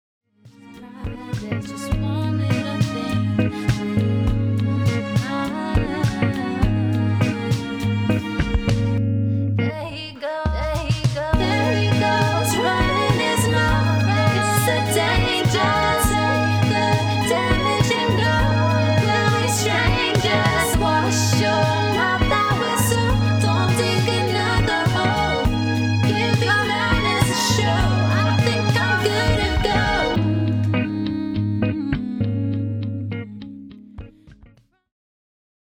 Pop/Dance